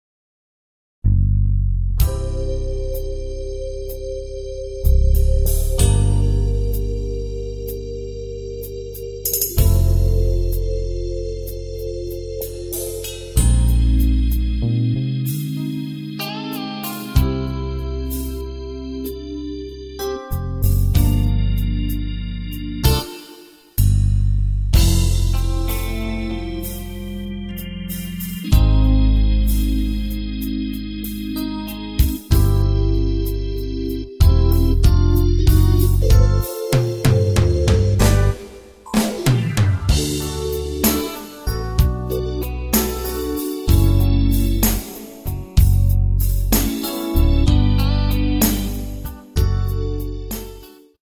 Key of C
Performance quality audio.